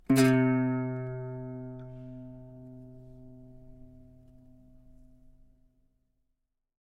玩具吉他 " 玩具吉他演奏
描述：一包我用在办公室找到的旧玩具吉他得到的一些有趣的声音:)希望这对某人有用。 装备：玩具吉他，Behringer B1，便宜的支架，Presonus TubePRE，MAudio Audiophile delta 2496.
标签： 卡通 字符串 玩具 玩具吉他 吉他
声道立体声